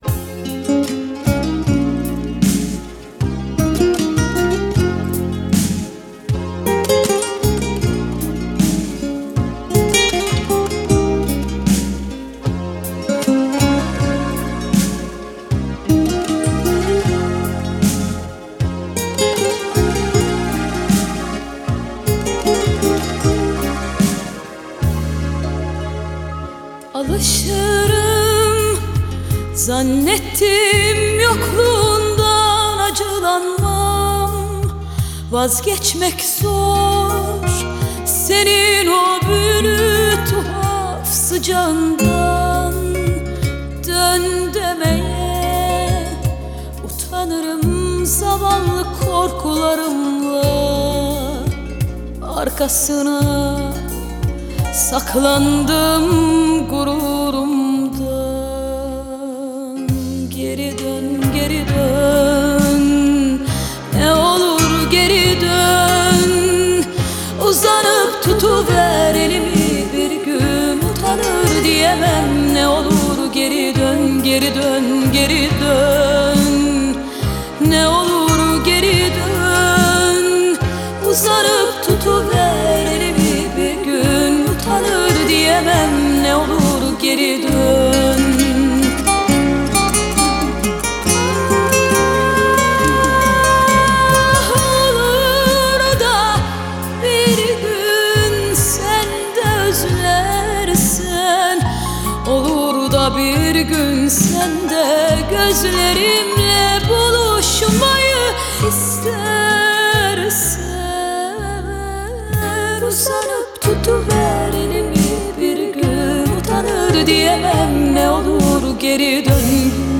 آهنگ ترکیه ای آهنگ غمگین ترکیه ای آهنگ نوستالژی ترکیه ای